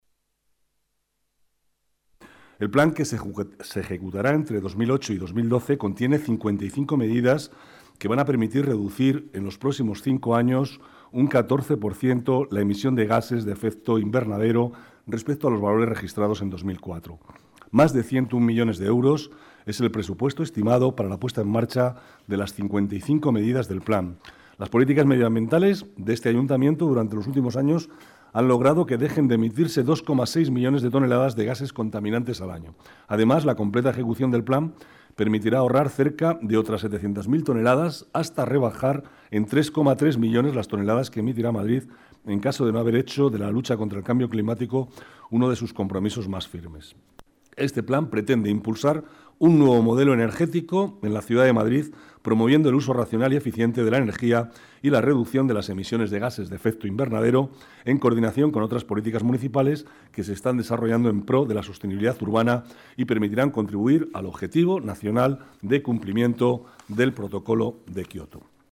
Nueva ventana:Declaraciones del vicealcalde, Manuel Cobo: 55 medidas contra el cambio climático